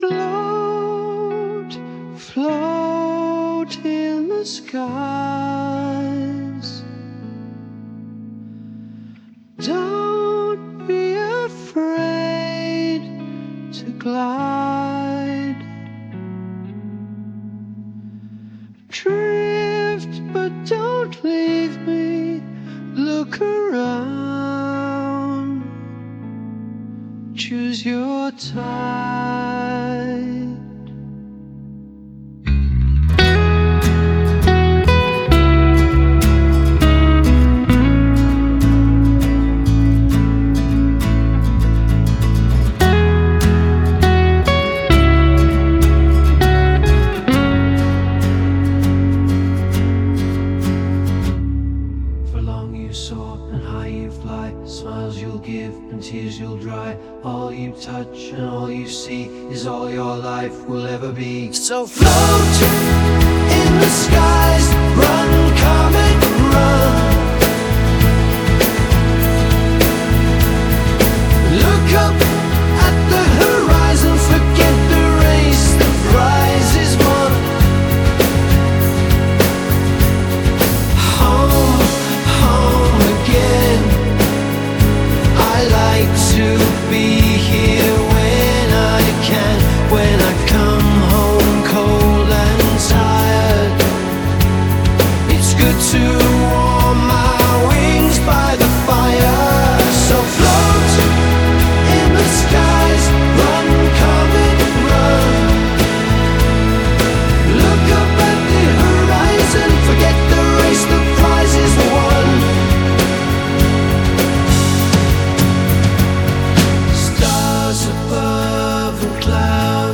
This is a simple cover to my bro's
Genre Melodic
Recorded track by track into Ableton Live.
acoustic guitars
main tune solo & bass.